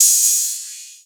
045 Group C1 - long hi hat luger.wav